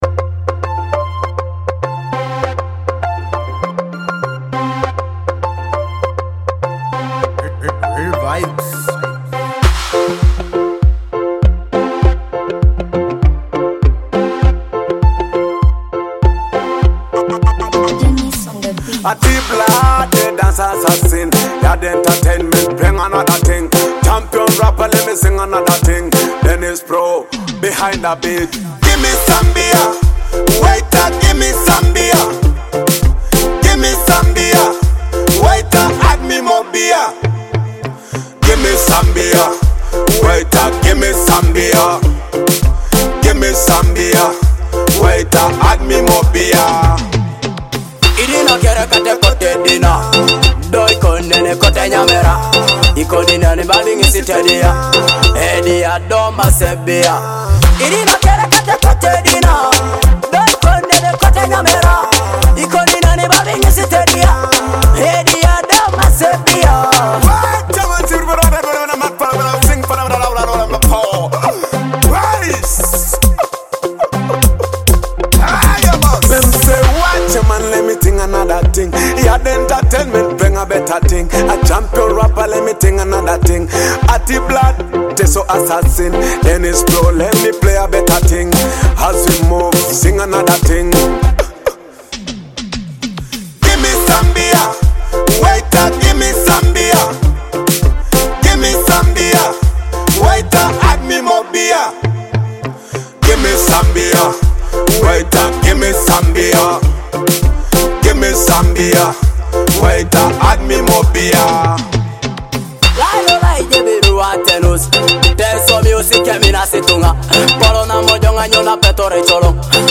a high-energy blend of dancehall rhythms and smooth vocals.